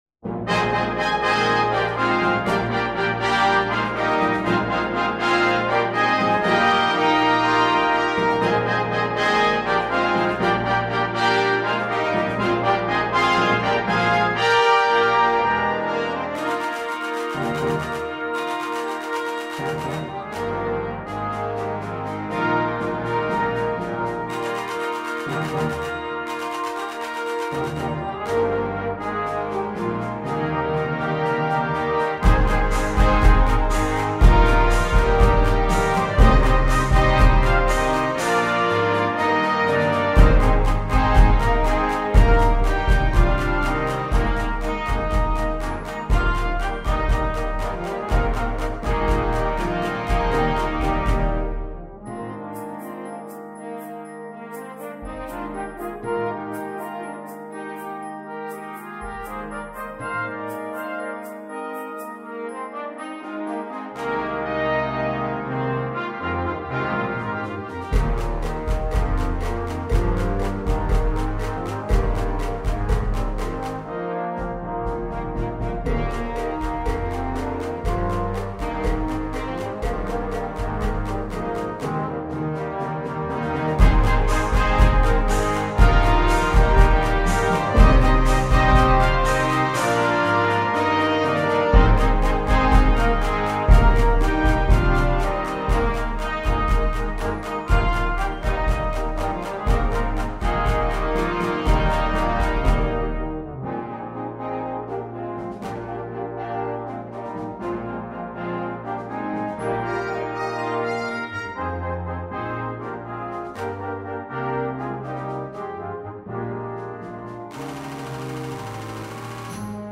sans instrument solo
Musique légère